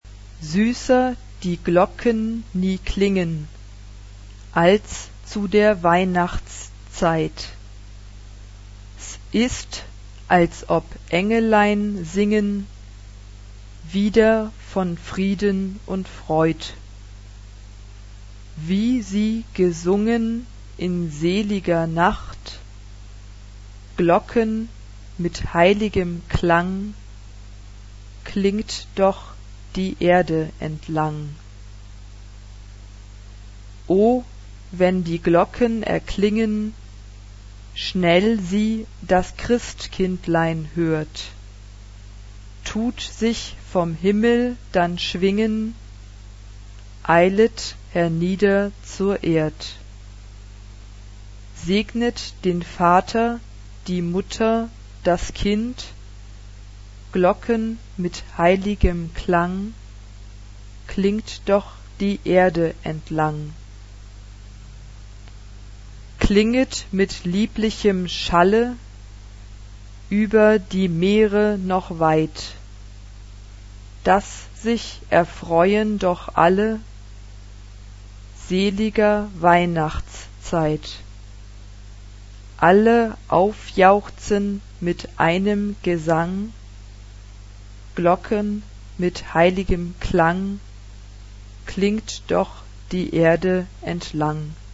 Genre-Style-Form: Sacred ; Secular ; Christmas song ; Traditional Mood of the piece: lively Type of Choir: SA (2 children voices ) Instrumentation: Piano (1 instrumental part(s))
Tonality: F major